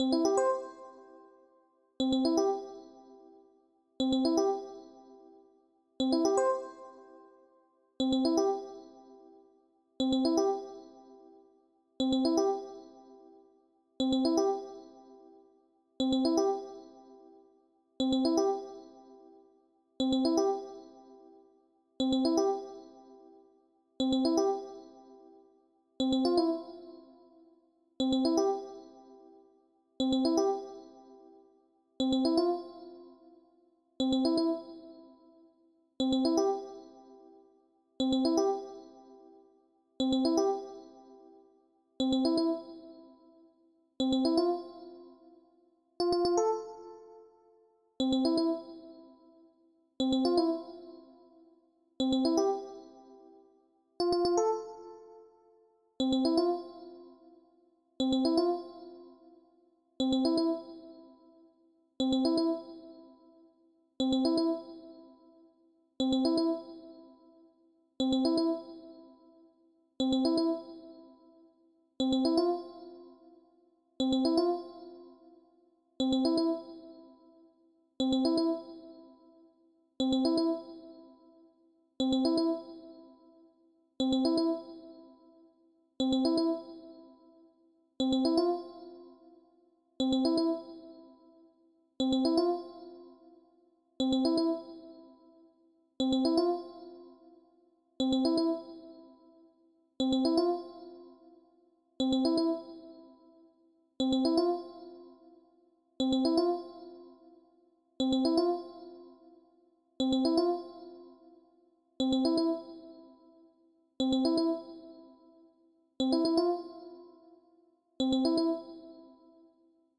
Jazz Calm 02:30